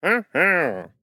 Minecraft Version Minecraft Version 1.21.5 Latest Release | Latest Snapshot 1.21.5 / assets / minecraft / sounds / mob / wandering_trader / yes4.ogg Compare With Compare With Latest Release | Latest Snapshot